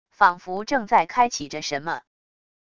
仿佛正在开启着什么wav音频